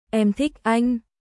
Em thích anhエム ティック アイン私（女）はあなた（男）が好きです